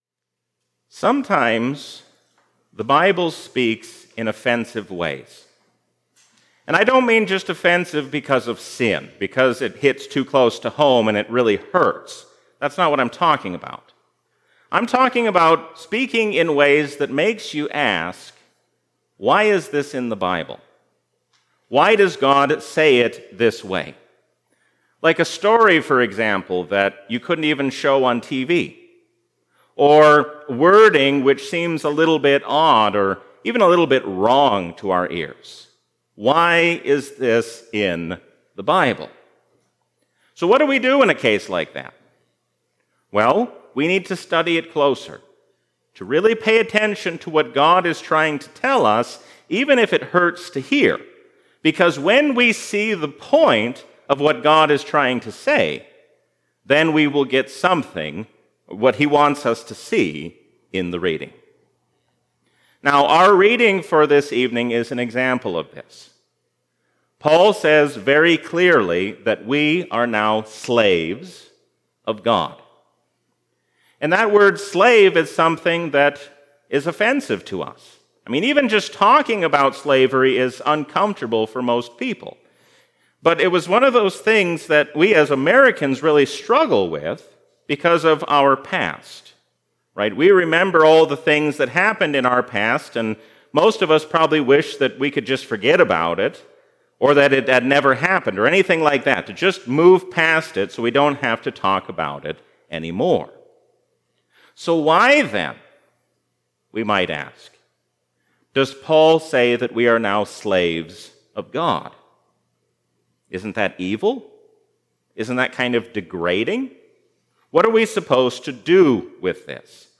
A sermon from the season "Trinity 2022." Stand firm against the hostile world, because Jesus reigns as the King of Kings and Lord of Lords forever.